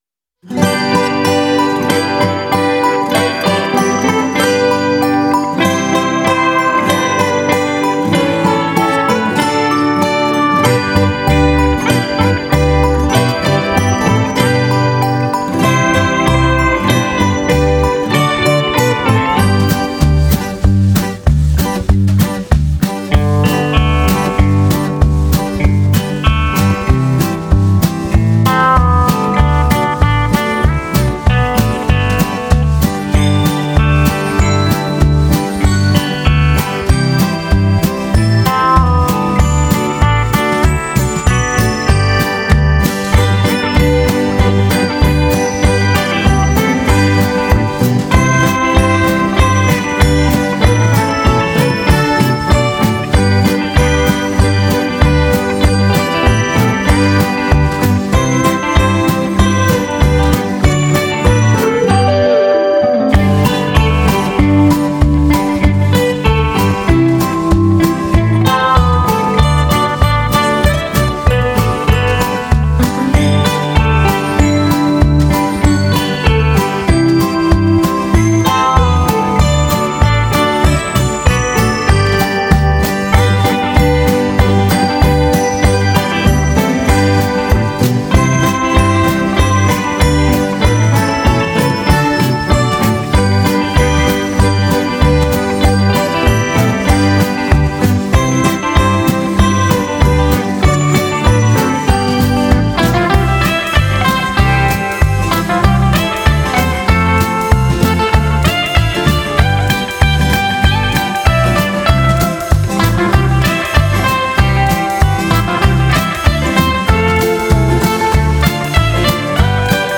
Jingle Bells - инструментальная версия 17 дек. 2023 г. Jingle Bells - инструментальная версия Скачать (114 скачали) Jingle bells, звон колокольчиков 02 янв. 2024 г. Просто звон колокольчиков Скачать (140 скачали)